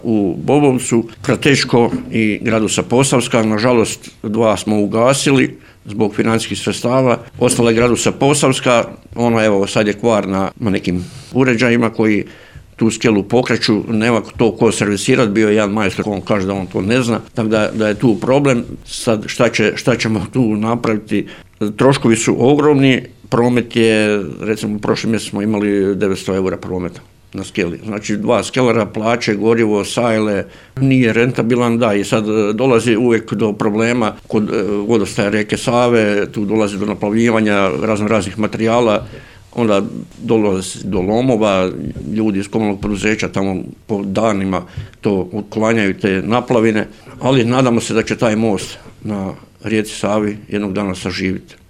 Načelnik Grga Dragičević